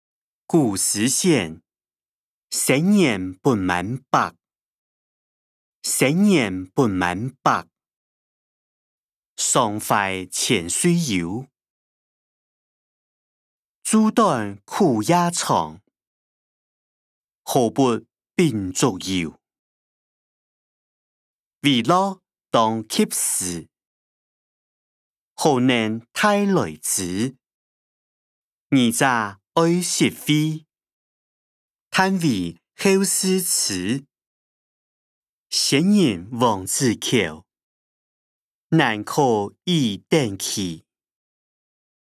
古典詩-生年不滿百音檔(四縣腔)